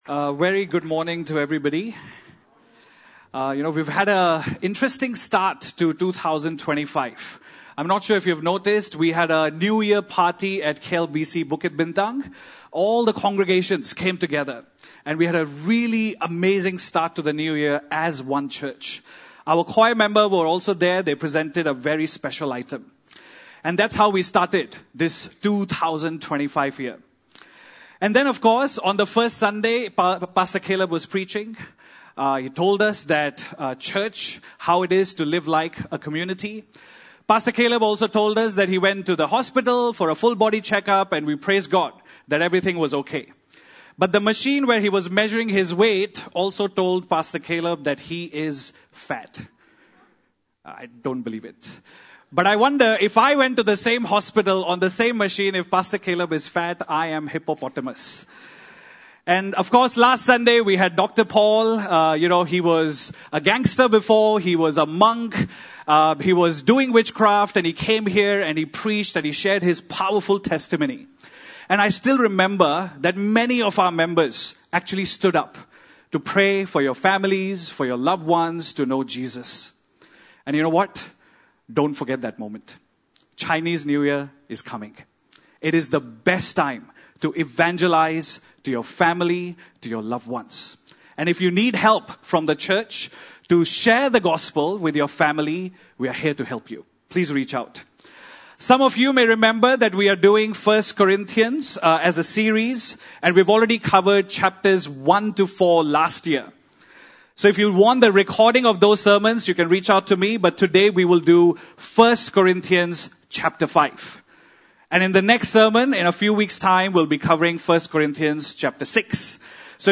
Passage: 1 Corinthians 5 Service Type: Sunday Service